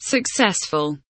successful kelimesinin anlamı, resimli anlatımı ve sesli okunuşu